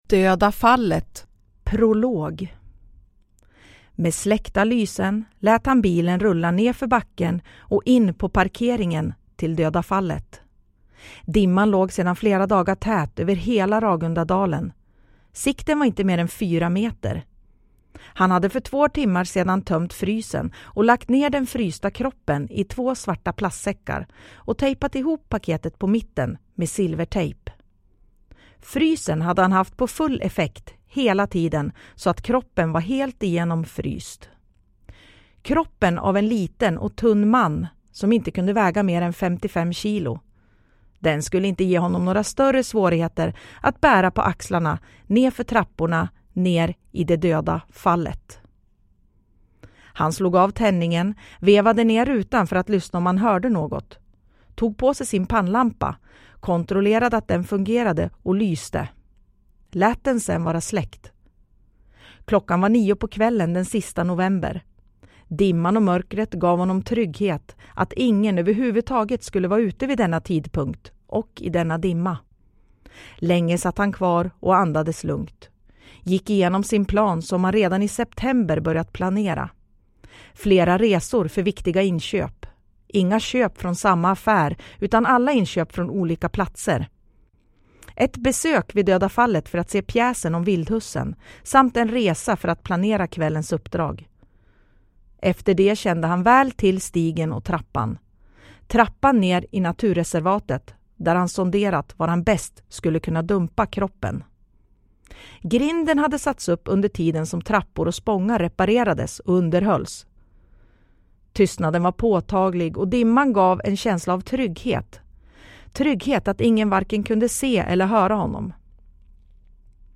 Döda Fallet – Ljudbok – Laddas ner